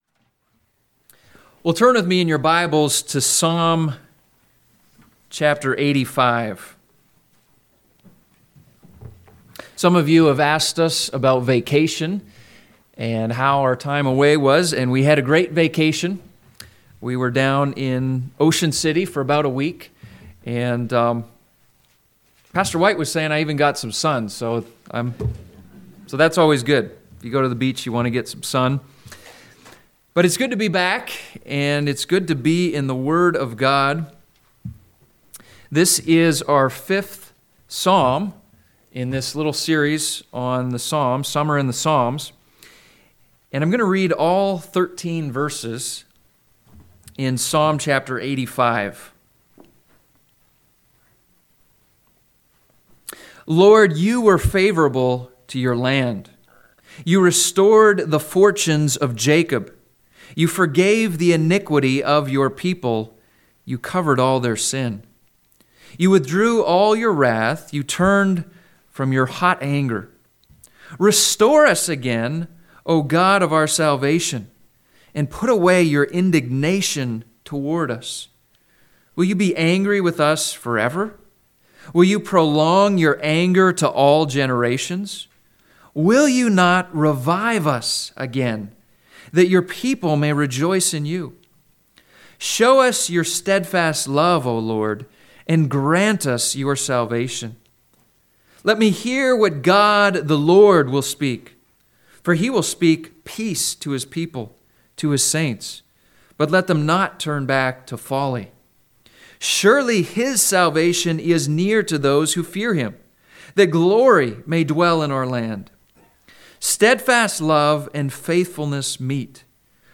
Home › Sermons › August 25, 2019